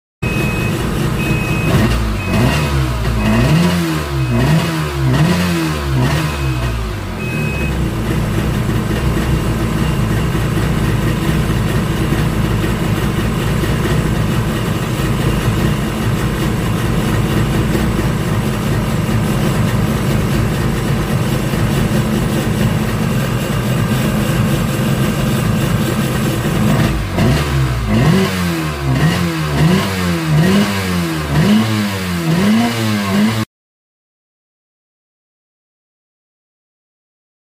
2 tempos jet ski vx700 sound effects free download